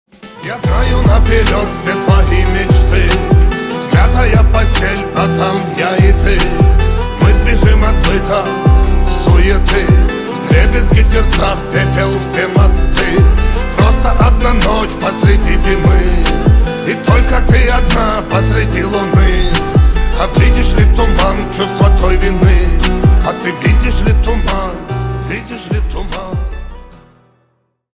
Русские Рингтоны » # Рэп Хип-Хоп